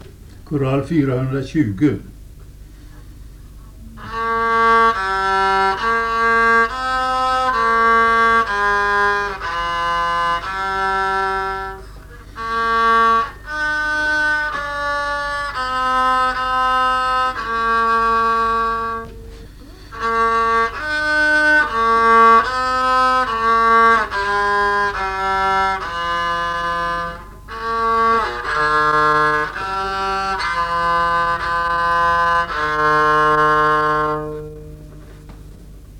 psalm
psalmodikon